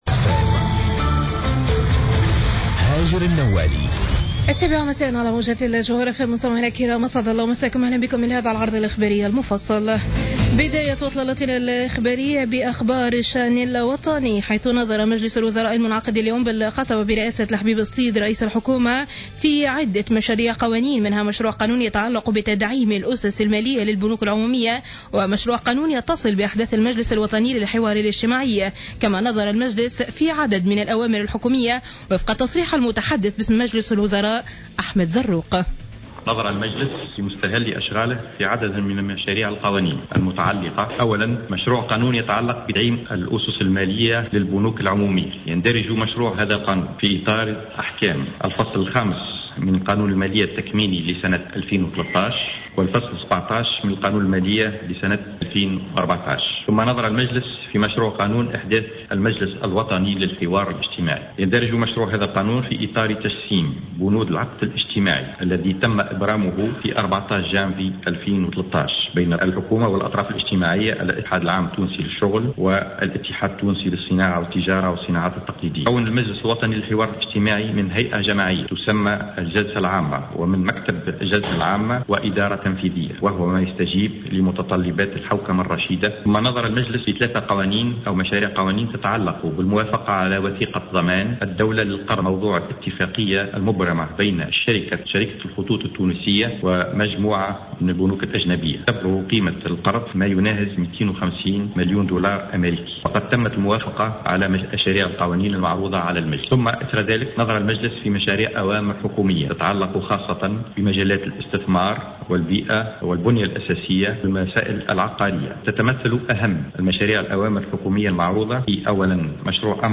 نشرة أخبار السابعة مساء ليوم الإربعاء 03 جوان 2015